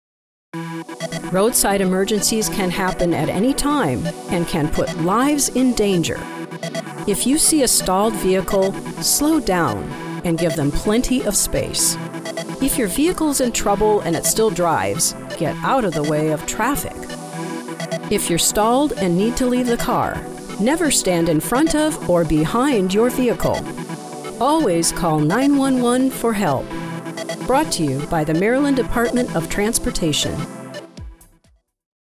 Professionally trained voice artist with a naturally refined and sophisticated delivery.
Roadside Emergency PSA - radio announcement, articulate, authoritative, informative, serious, straightforward